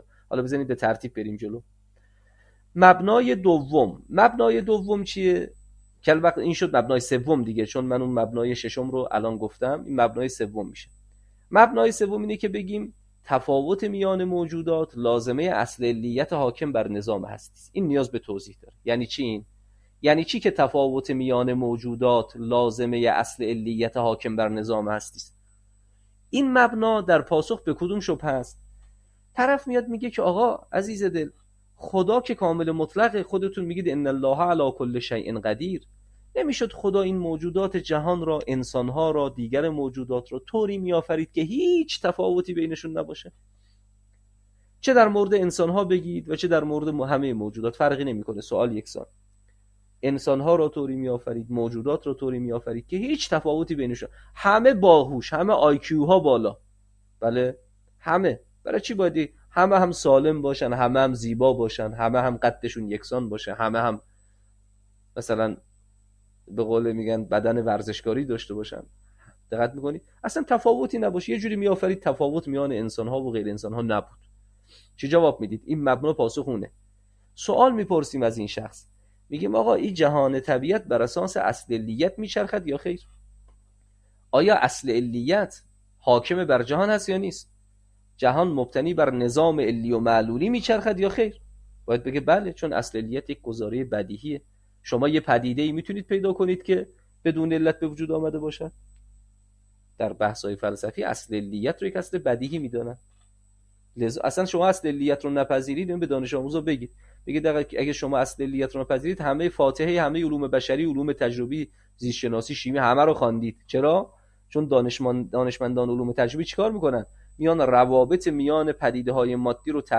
تدریس کلام تطبیقی